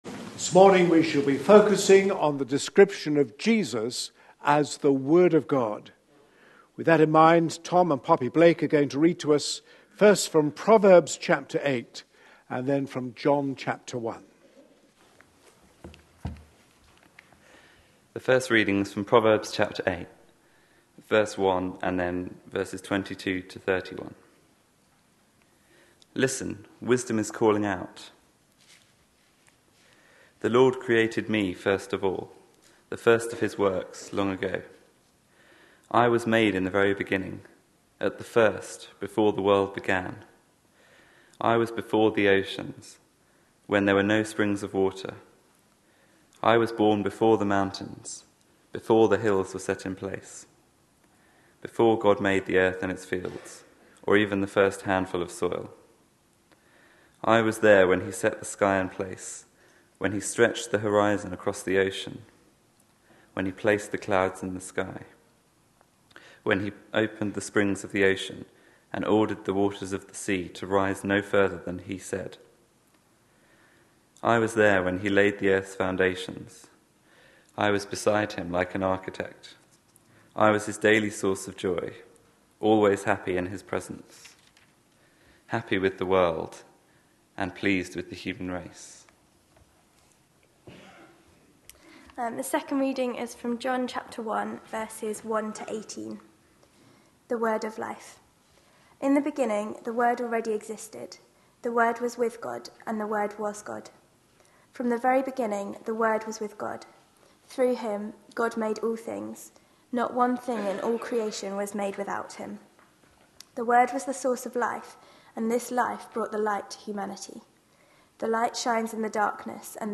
A sermon preached on 22nd December, 2013.